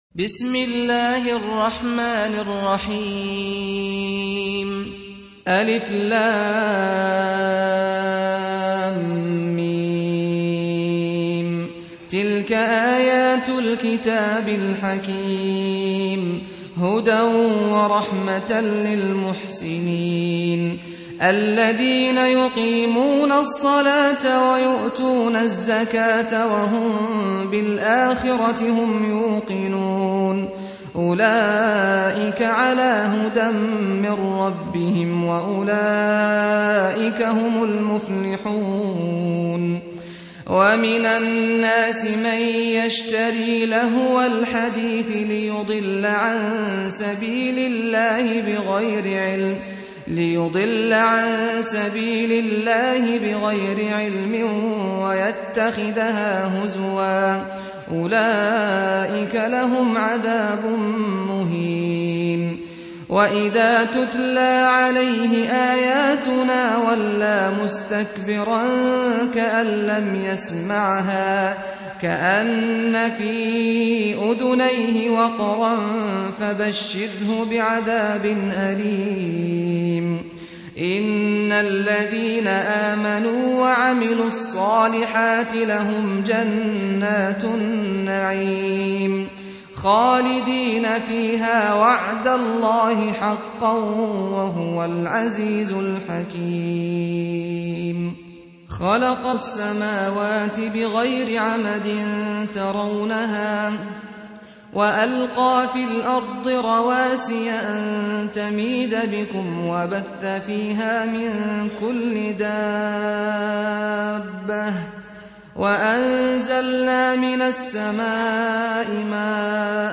31. Surah Luqm�n سورة لقمان Audio Quran Tarteel Recitation
Surah Repeating تكرار السورة Download Surah حمّل السورة Reciting Murattalah Audio for 31.